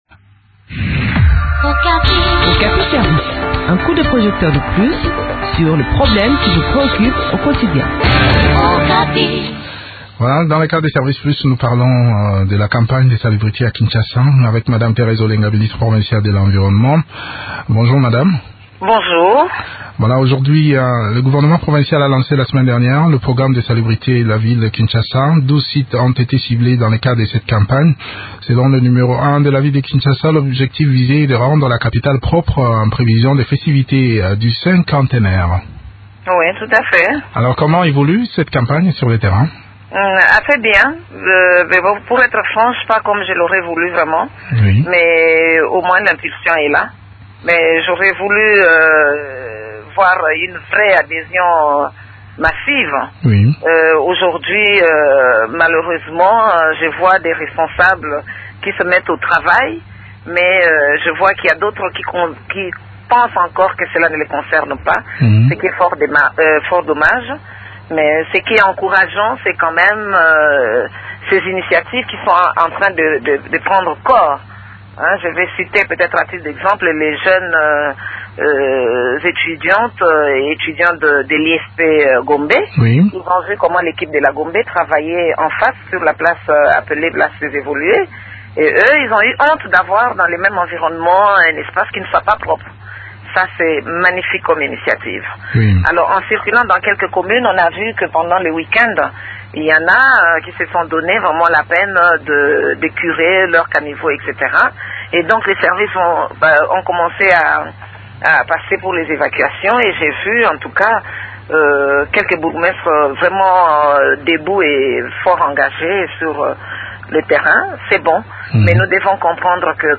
s’entretient sur le déroulement de cette campagne avec Thérèse Olenga, ministre provinciale de l’Environnement.